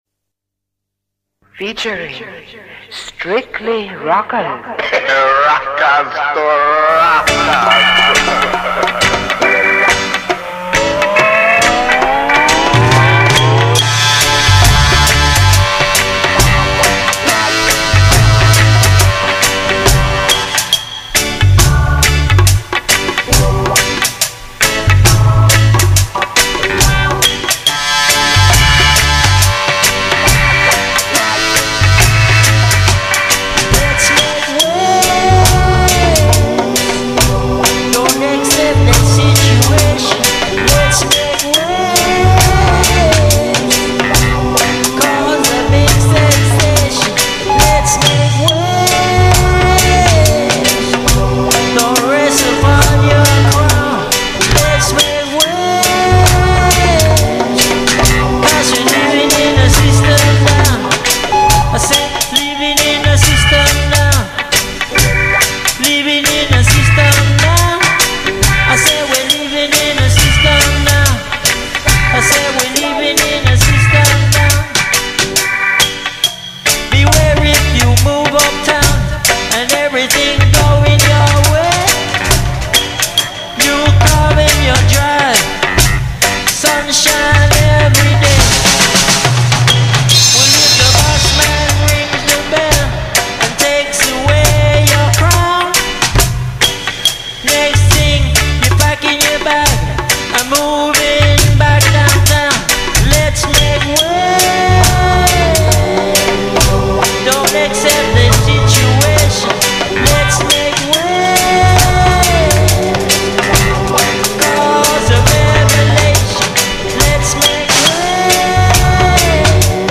an underground classic
Inspired by (to my ears) The Wailers and The Clash.